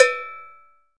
DDW6 PERC 2.wav